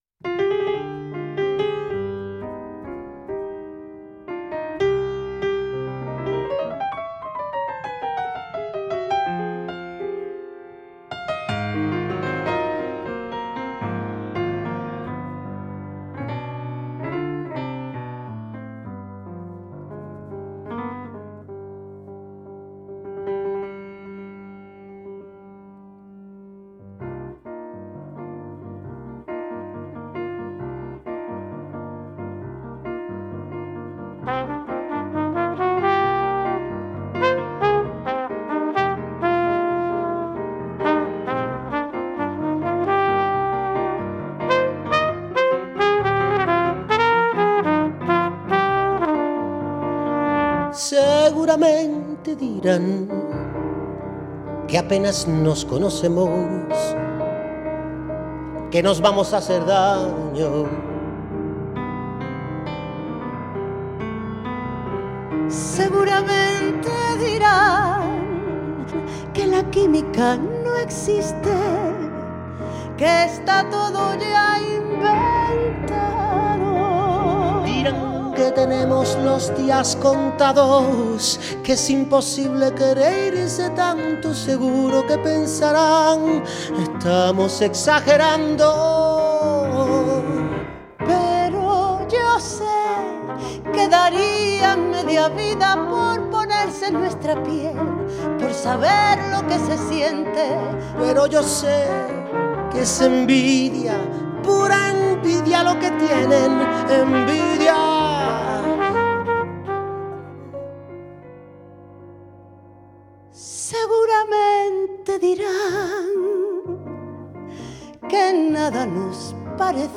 un disco de duetos